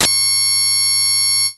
描述：从破损的Medeli M30合成器上录制的一次性样本